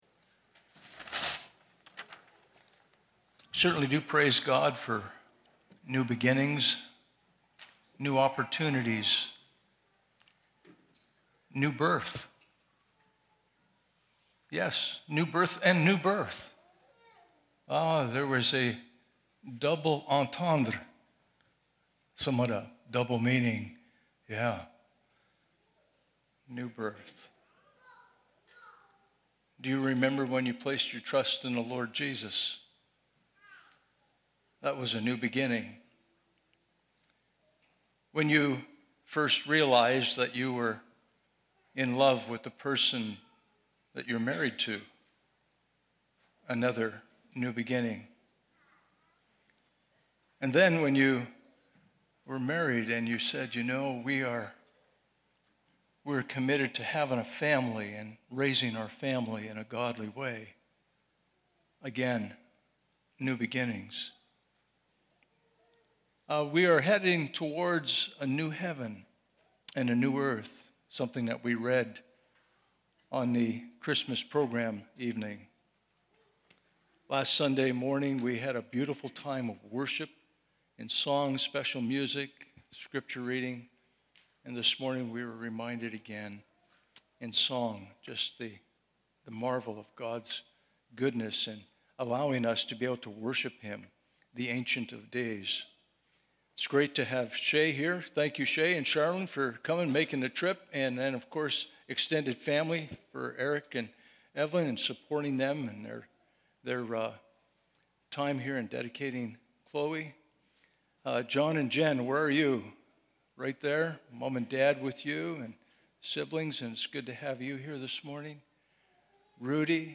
Mark 10:13-16 Service Type: Sunday Morning « Christmas Morning Service Will you give Jesus access to your needs?